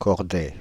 French pronunciation of « Cordey »
Fr-Cordey.ogg